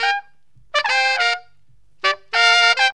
HORN RIFF 16.wav